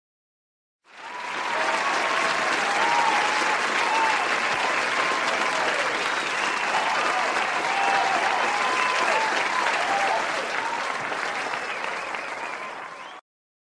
Crowd Cheers And Applause